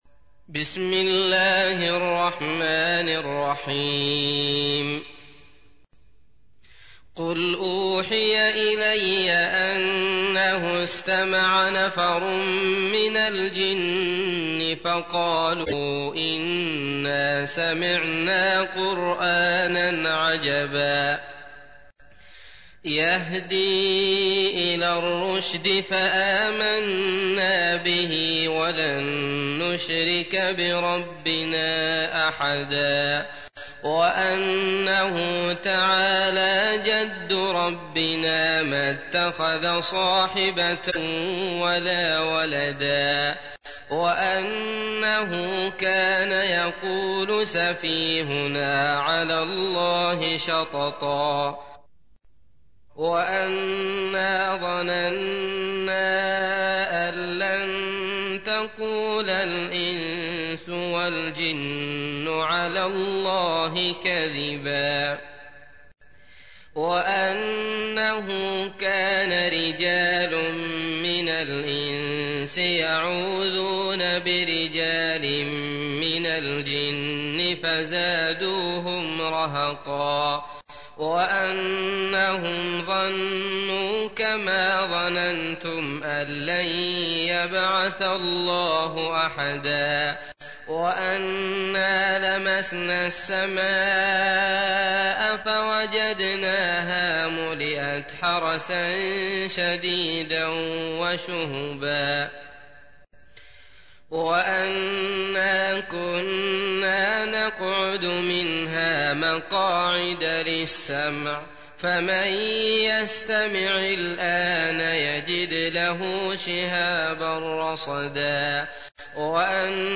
تحميل : 72. سورة الجن / القارئ عبد الله المطرود / القرآن الكريم / موقع يا حسين